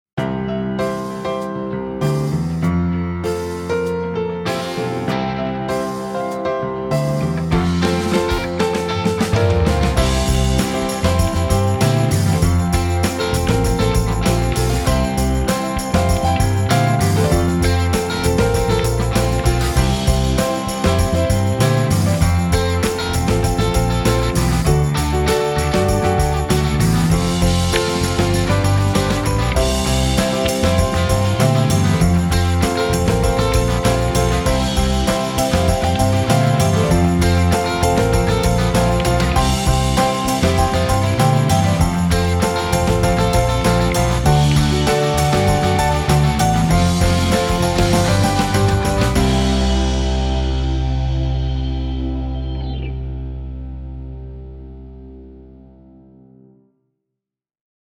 采样自经典立式钢琴，音色平衡而高动态，富有古典韵味。
高雅复古立式钢琴
来自1908年的复古立式钢琴采样
经典的立式钢琴，音色均衡，动态宽广，音色优美丰厚。
声音类别: 立式钢琴